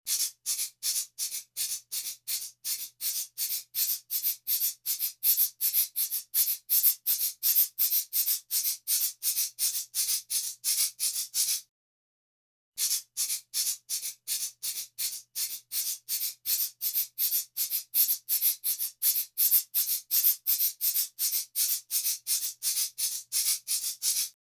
Audio test: Localizzazione spaziale del suono
sinistra -> dietro -> destra
13-audiocheck.net_LEDR_Behind.wav